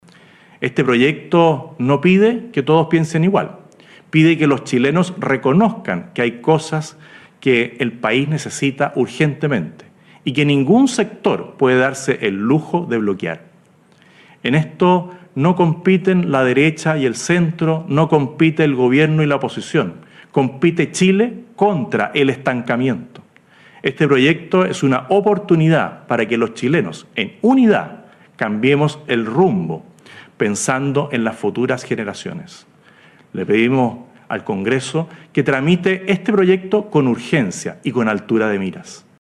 En su primera cadena nacional, el Presidente José Antonio Kast realizó un balance de su primer mes de gobierno y anunció el envío al Congreso del proyecto de ley de Reconstrucción y Desarrollo Económico y Social para “impulsar el crecimiento, el empleo y la seguridad en el país”.
cuna-04-cadena-nacional-Kast.mp3